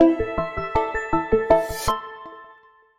Звуки джинглов
• Качество: высокое